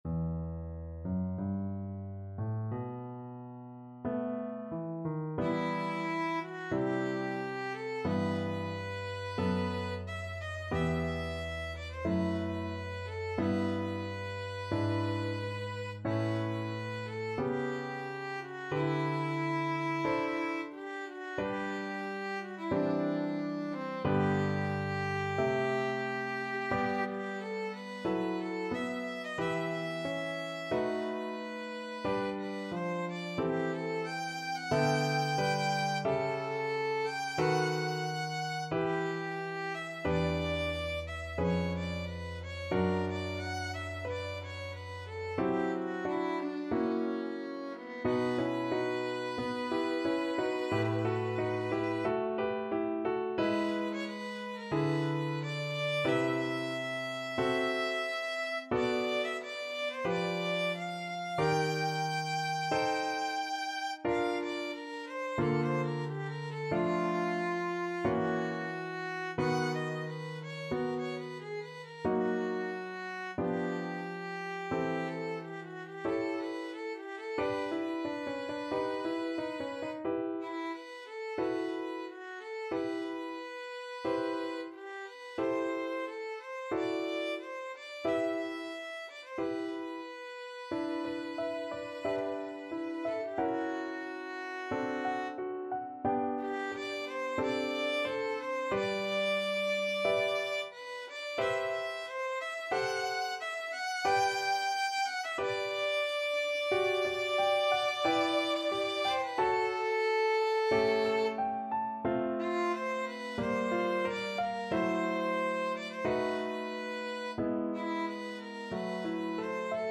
Classical Faure, Gabriel Piece (Vocalise-Etude) Violin version
Violin
His music is characterized by unusual harmonies and modulations
4/4 (View more 4/4 Music)
E minor (Sounding Pitch) (View more E minor Music for Violin )
Adagio, molto tranquillo (=60) =45
Classical (View more Classical Violin Music)